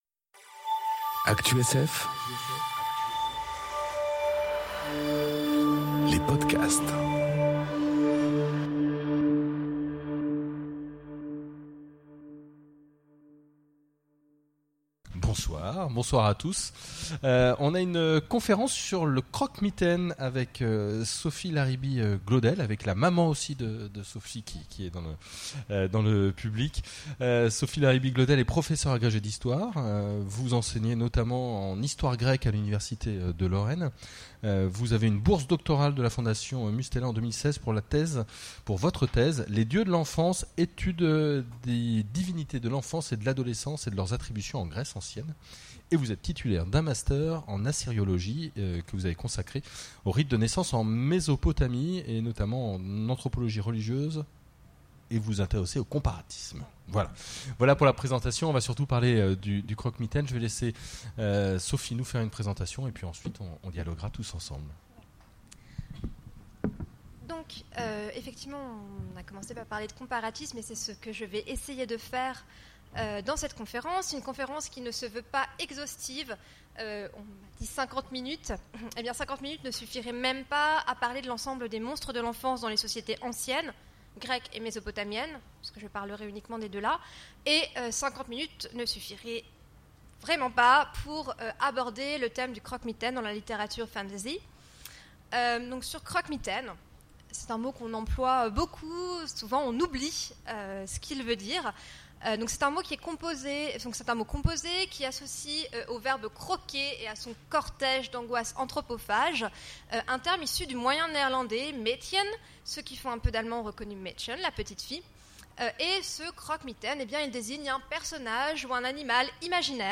Conférence Le croque-mitaine (et autres figures de l'épouvante)... Une source de la fantasy ? enregistrée aux Imaginales 2018